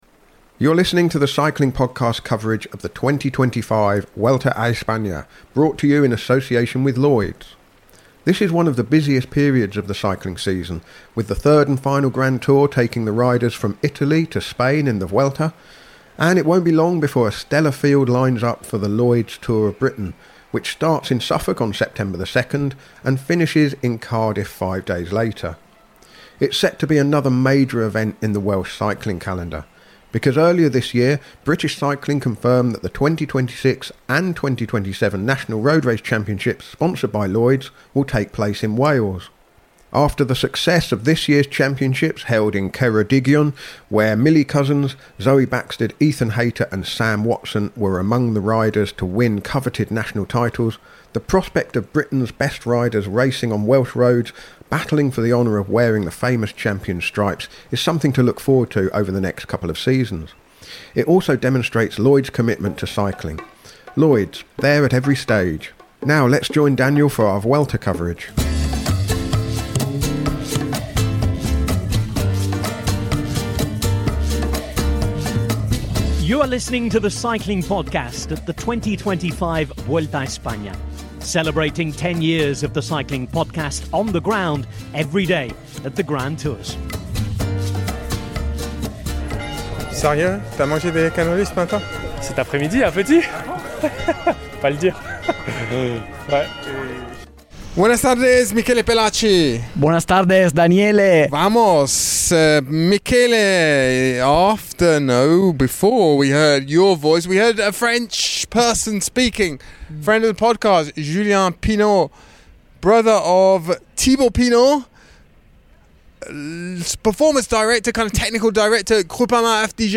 Join us for daily coverage of the Vuelta a España recorded on the road as the race makes its way from Turin to Madrid. Our daily coverage features race analysis, interviews and daily postcards from Spain.